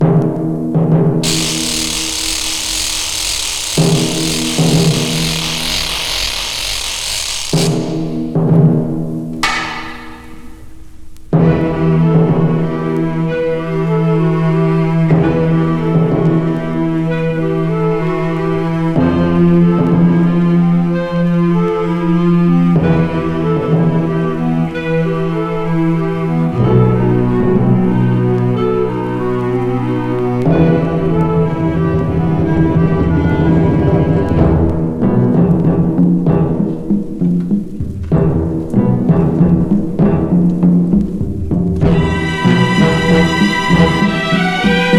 金管楽器の様々な表情、ストリングスにフルートが醸し出す魅惑さ、打楽器が生み出す土着さと洗練さを織り成す強烈かつ大興奮。
Jazz, Latin, Easy Listening, Mambo　USA　12inchレコード　33rpm　Mono